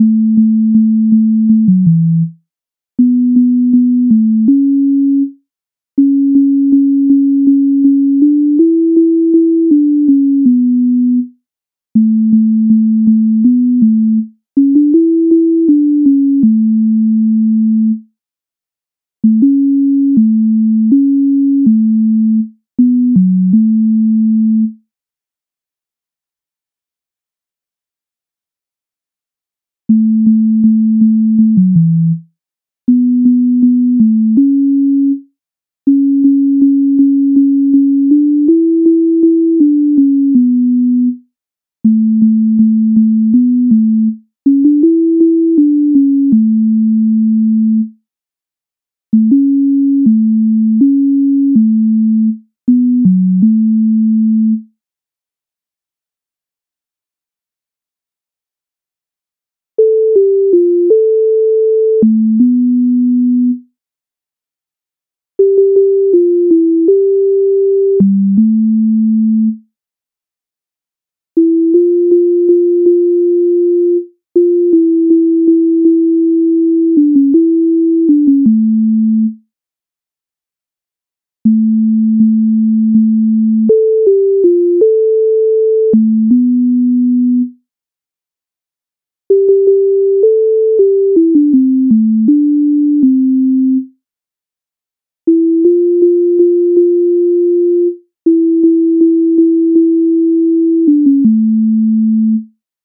MIDI файл завантажено в тональності A-dur